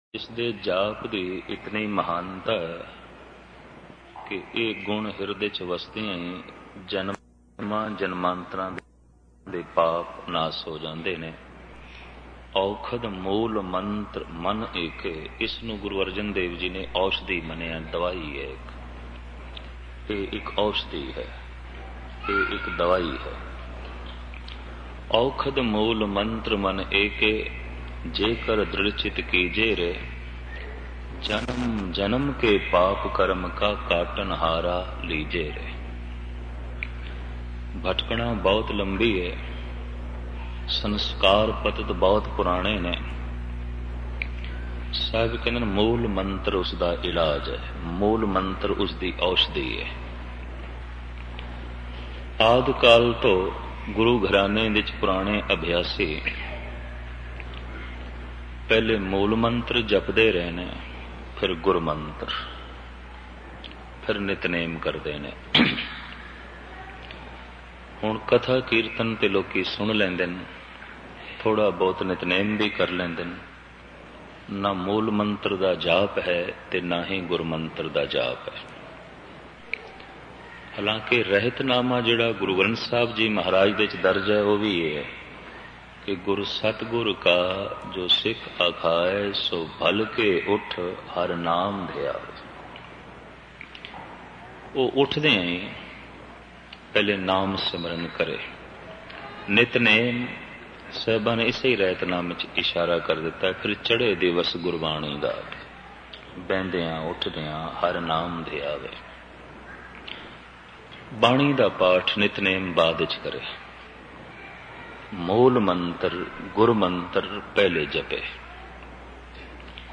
Album: RAS METHA RAS MAAS Genre: Gurmat Vichar